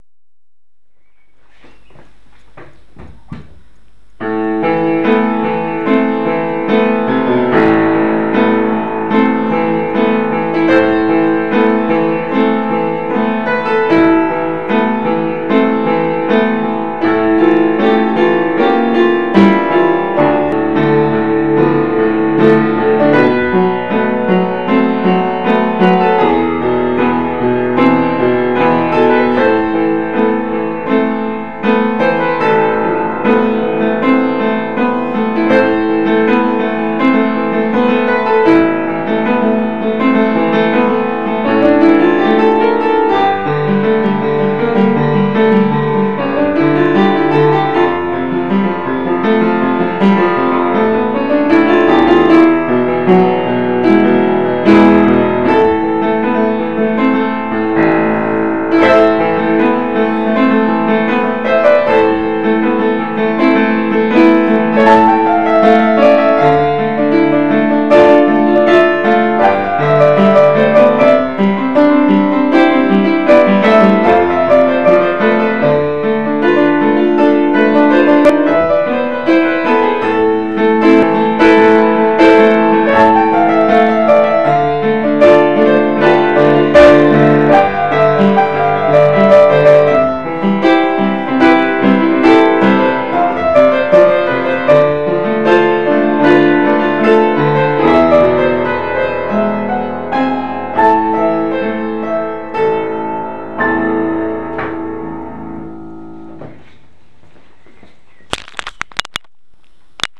הגרסה היא נגינה חיה של בלבד, ואילו הגרסה השנייה נכתבה לכמה כלים בתוכנה לכתיבת תווים.